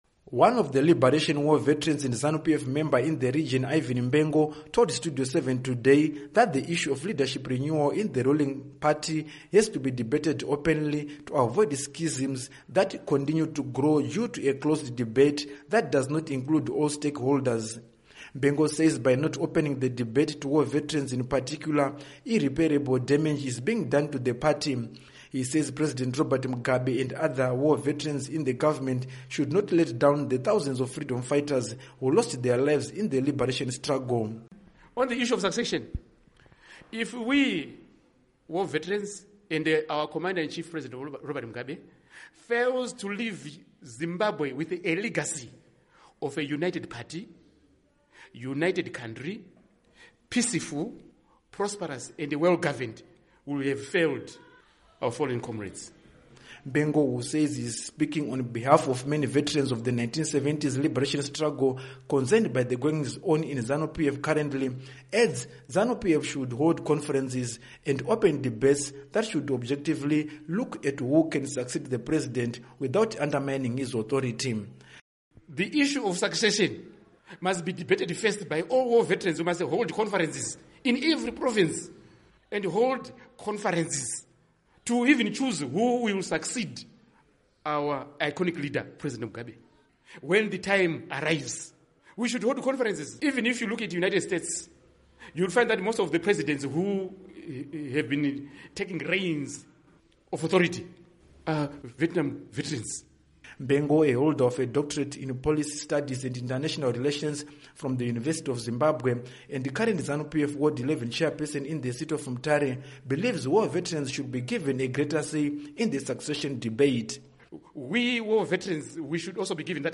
Report on Mugabe Succession Debate